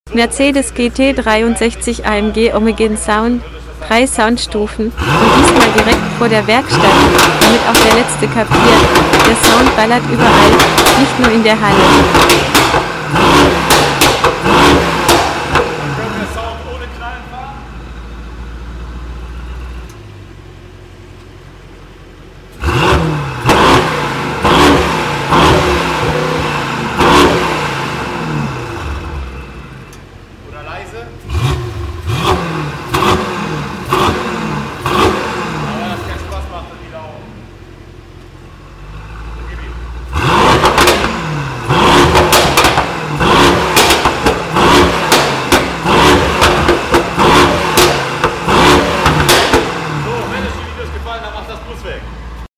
Mercedes GT63 AMG by HDH-CONCEPTS